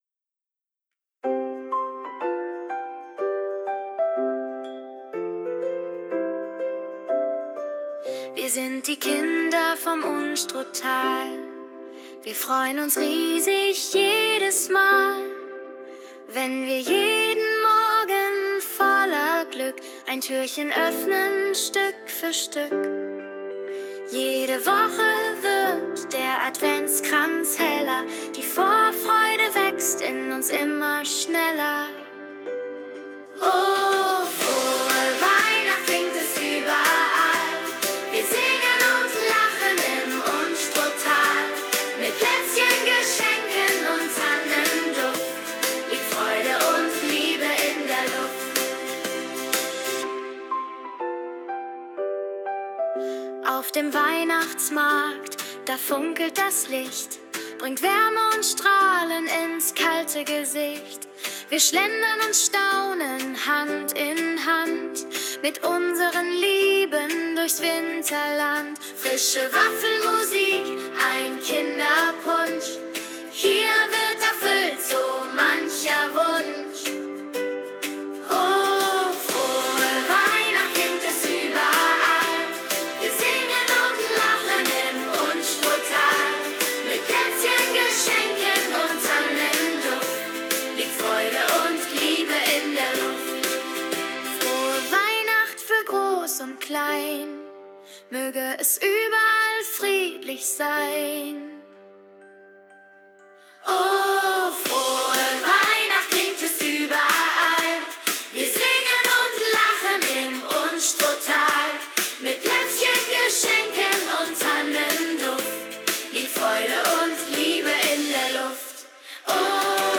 Der Chor singt bei den Weihnachtsaufführungen 2025